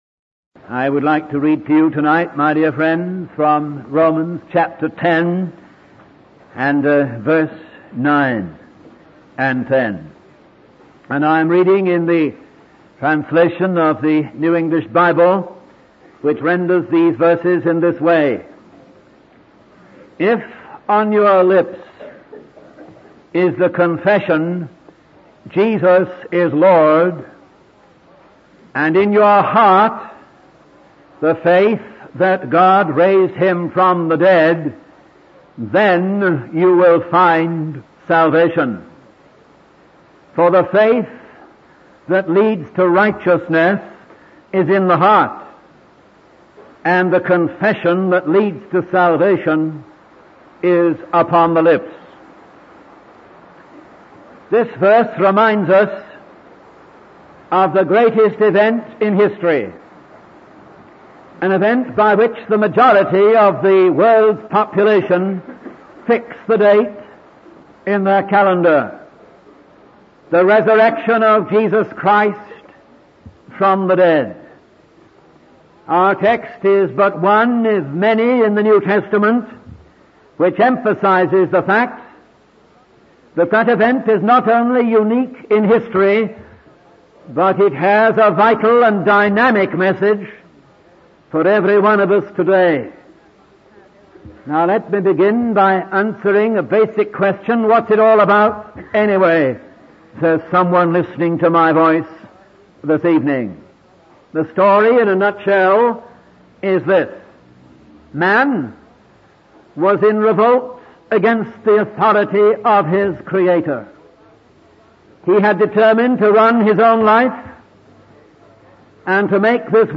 In this sermon, the speaker explains that the story of humanity is one of rebellion against God's authority.